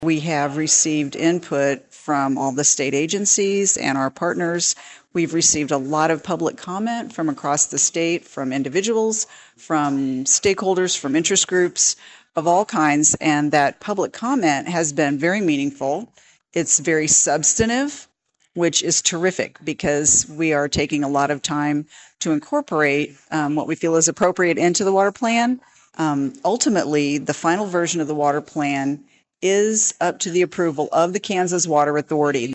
During a break in the meeting, Kansas Water Office Director Connie Owen spoke with KVOE News regarding the state’s water plan.